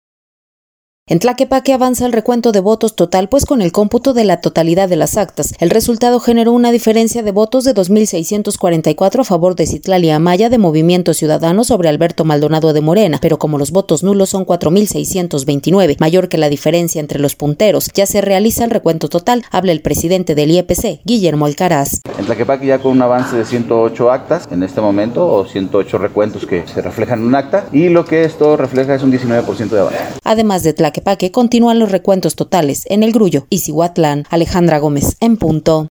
En Tlaquepaque avanza el recuento de votos total, pues con el cómputo de la totalidad de las actas, el resultado generó una diferencia de votos de dos mil 644 a favor de Citlalli Amaya de Movimiento Ciudadano, sobre Alberto Maldonado de Morena, pero como los votos nulos son cuatro mil 629, mayor que la diferencia entre los punteros, ya se realiza el recuento total. Habla el presidente del IEPC Guillermo Alcaraz.